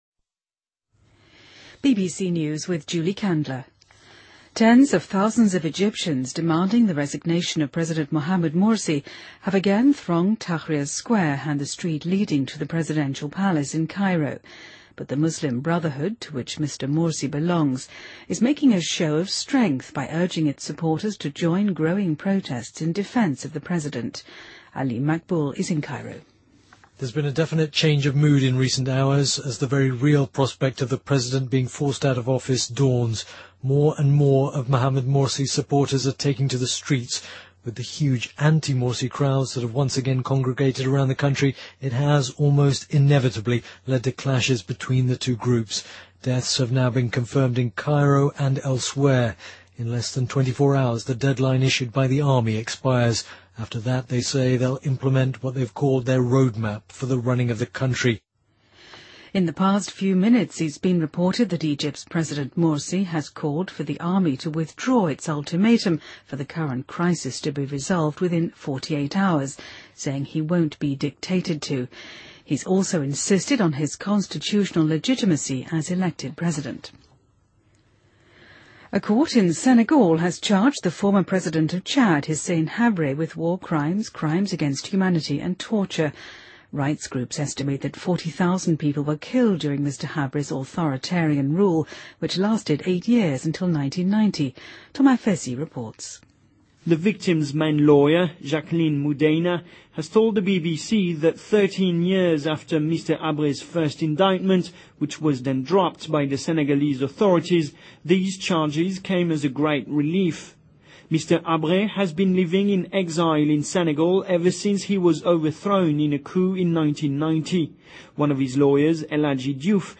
BBC news,纳尔逊·曼德拉家族的16名成员指控前总统的长孙以刑事指控